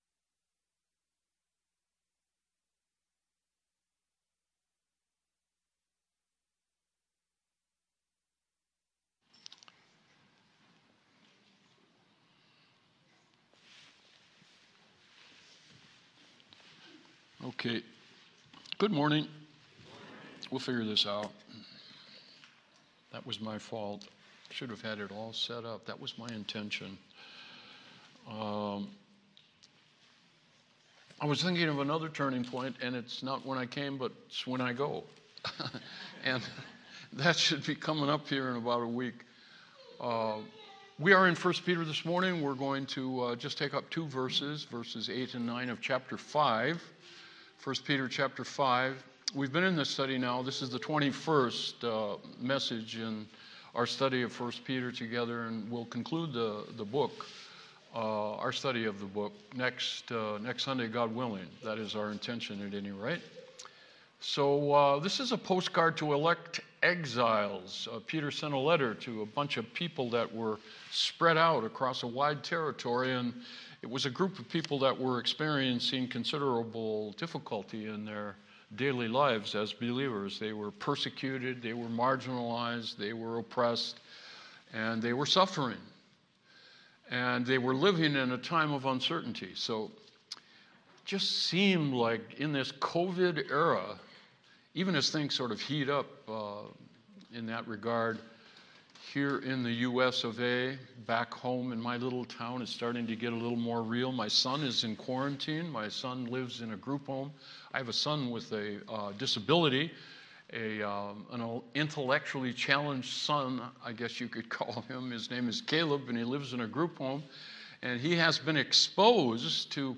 Worship Service 11/15/20
Passage: 1st Peter Service Type: Worship Service